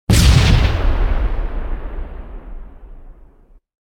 otherhit3.ogg